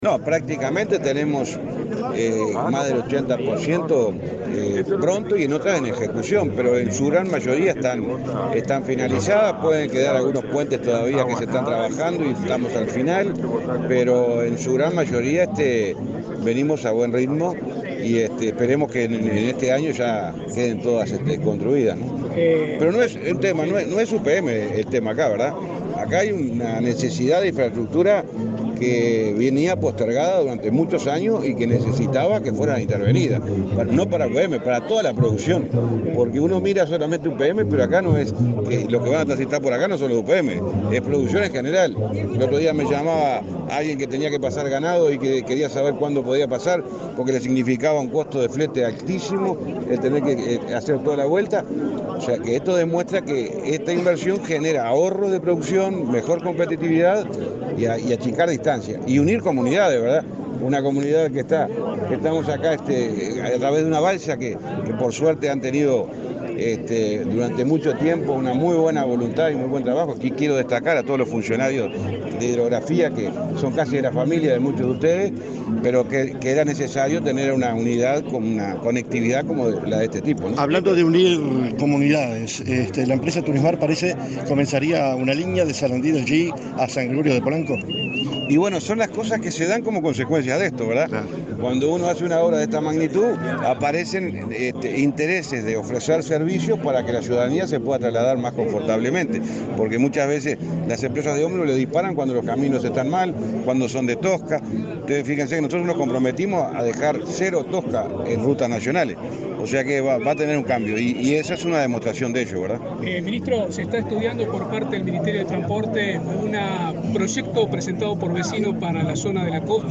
Declaraciones del ministro de Transporte, José Luis Falero
Antes dialogó con la prensa.